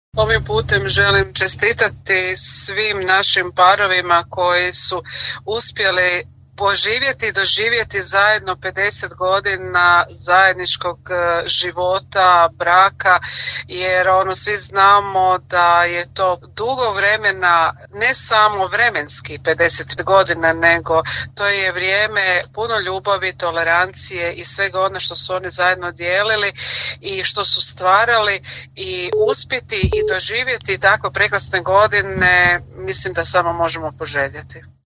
Evo što o današnjoj proslavi u ime organizatora Grada Daruvara kaže zamjenica gradonačelnika Vanda Cegledi